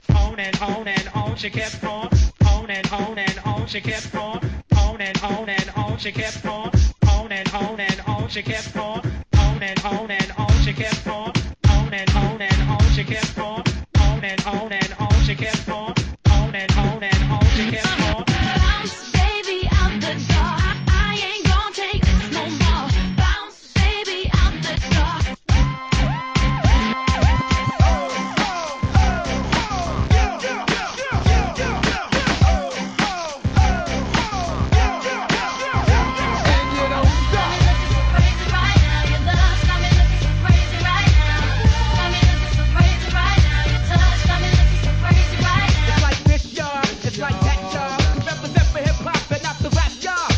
HIP HOP/R&B
2003年〜2004年ヒット曲のMEGAMIX物!!